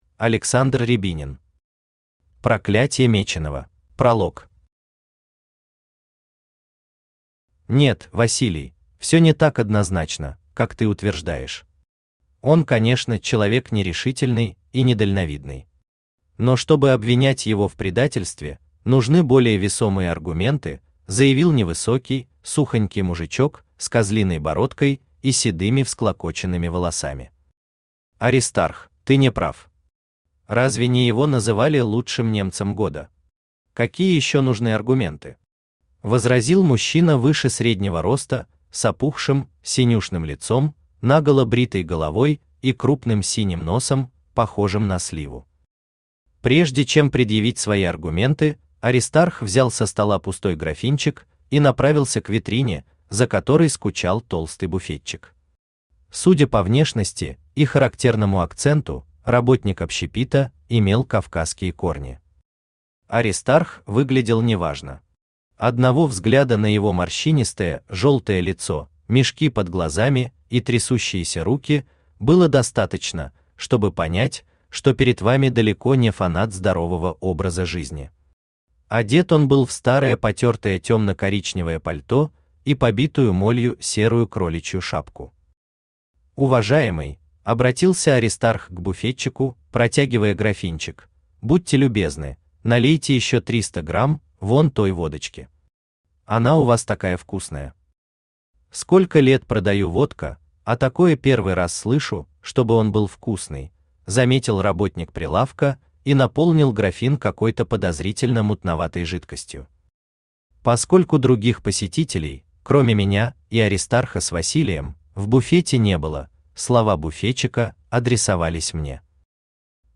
Аудиокнига Проклятье Меченого | Библиотека аудиокниг
Aудиокнига Проклятье Меченого Автор Александр Рябинин Читает аудиокнигу Авточтец ЛитРес.